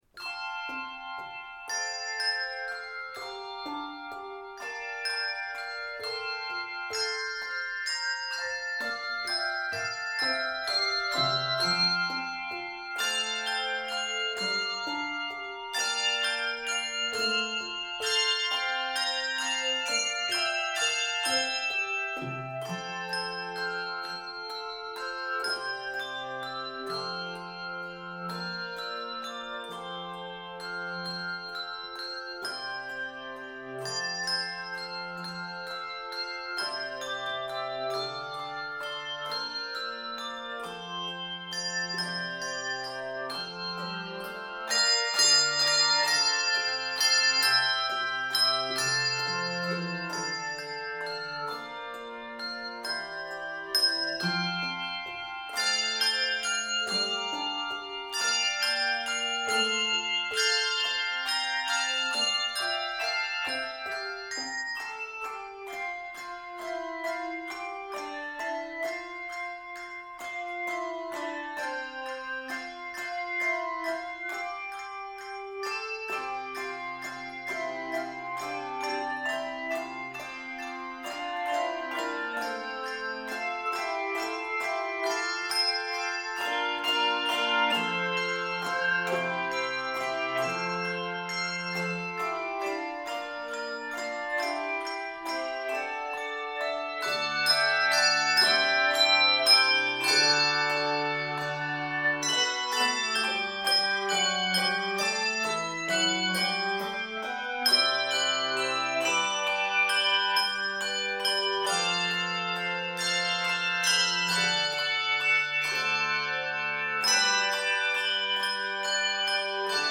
Keys of F Major and G Major.